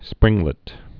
(sprĭnglĭt)